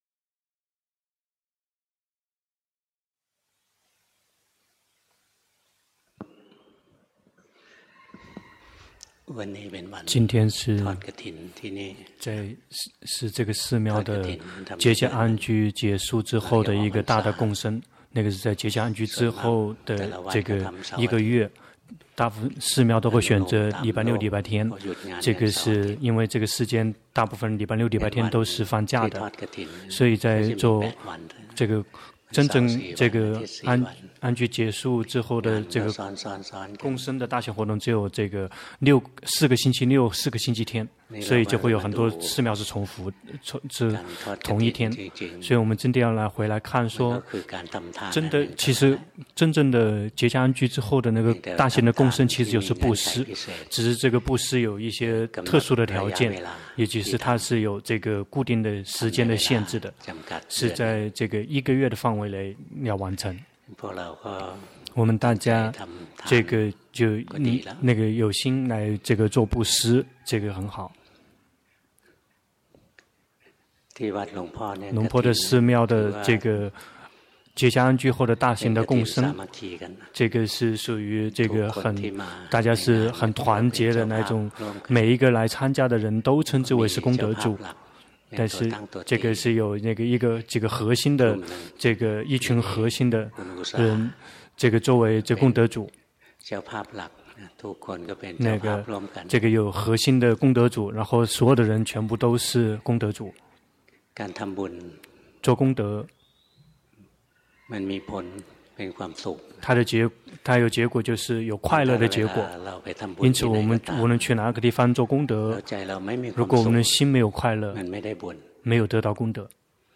同聲翻譯